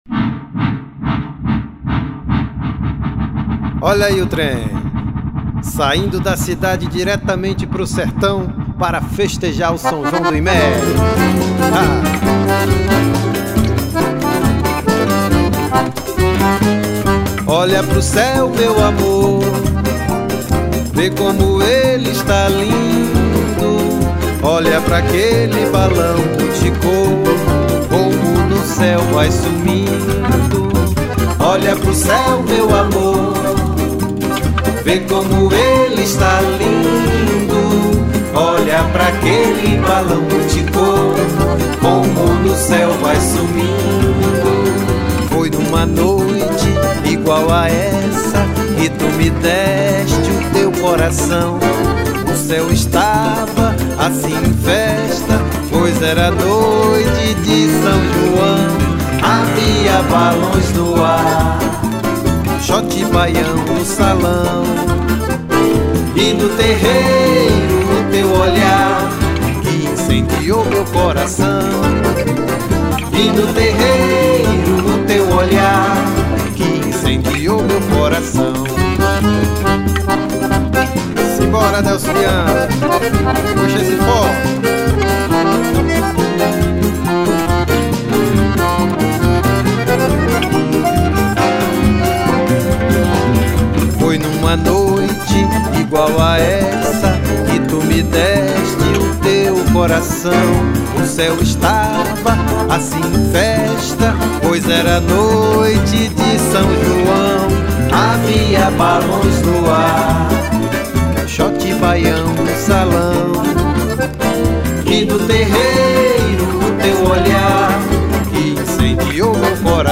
1447   04:11:00   Faixa:     Forró